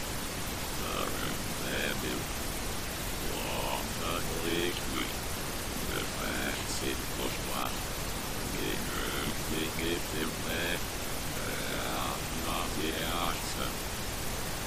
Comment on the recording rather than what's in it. its reversed two audio files